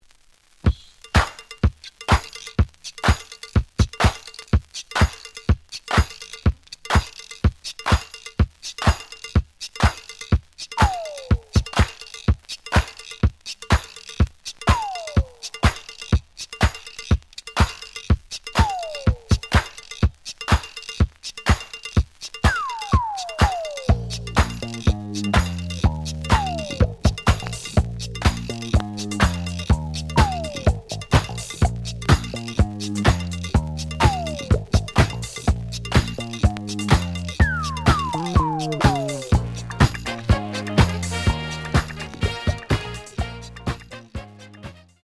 The audio sample is recorded from the actual item.
●Genre: Disco
Slight noise on B side.